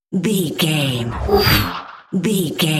Sci fi horror whoosh fast
Sound Effects
Atonal
Fast
ominous
eerie
whoosh